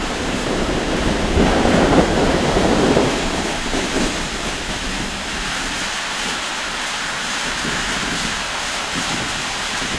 Звук метели:
snowstorm1.wav